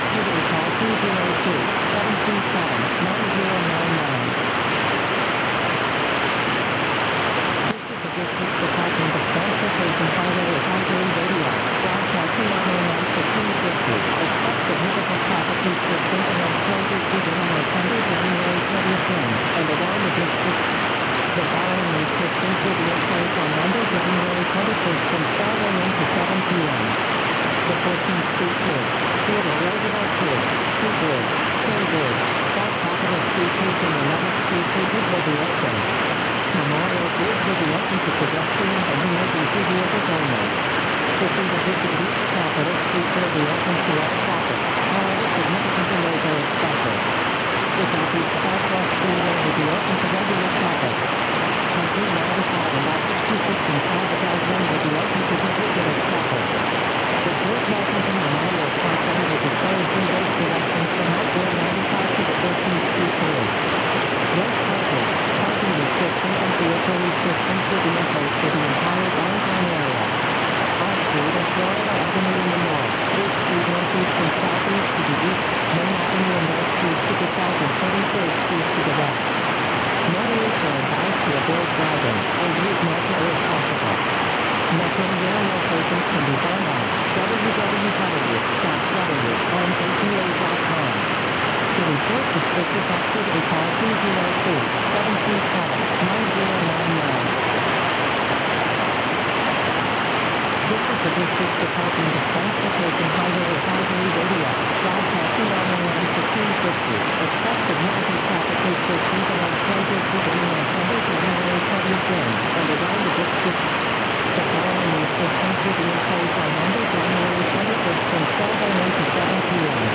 Na gravação, que podia ser escutada na frequência 1650 kHz do AM, mas só em algumas áreas do município, havia um alerta para os ouvintes da emissora informando sobre a interdição de ruas para a posse do então presidente dos EUA Barack Obama (naquele dia, ele iniciava o segundo mandato).
Como é possível notar, trata-se de uma transmissão cheia de interferências e ruídos, dificultando o entendimento da mensagem em sua maior parte. Um dos alertas identificados pelo pesquisador pede aos moradores para evitar a “ponte da 14th Street”, que estaria interditada naquela data.